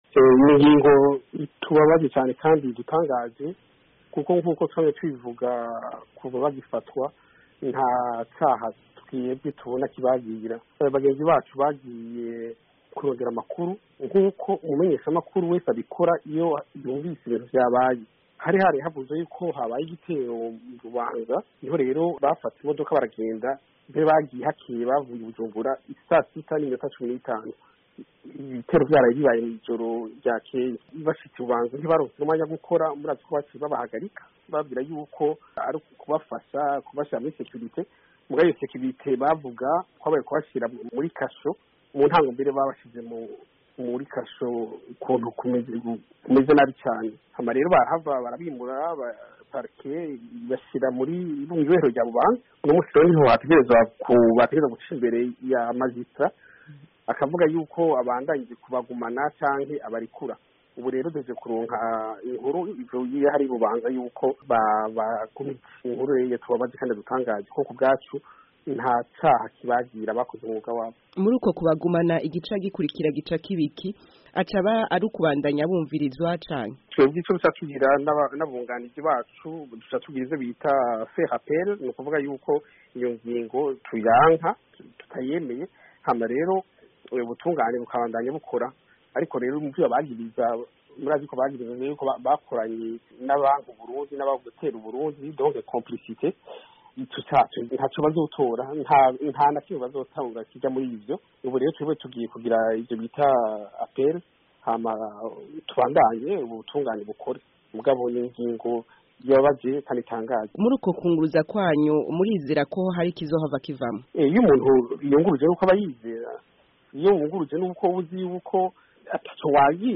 Mu kiganiro